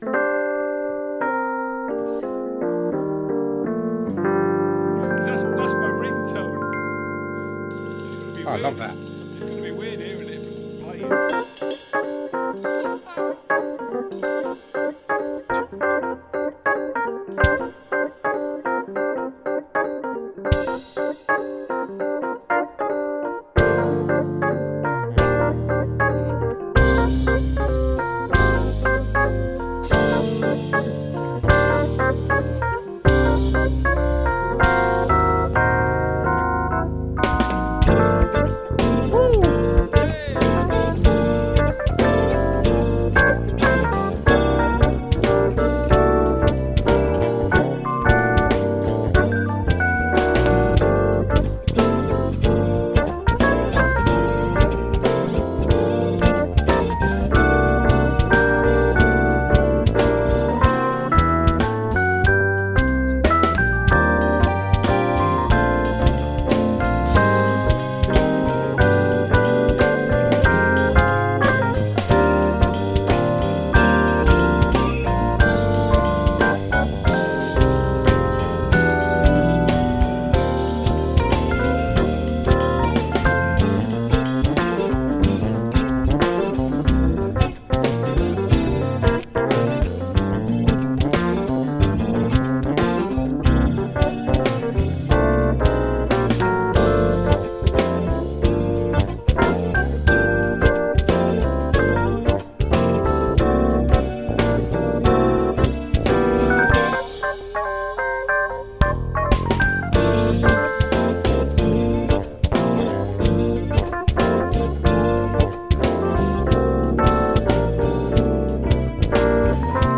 Here's a track from last night's # Jamulus session called 'We Don't Know.'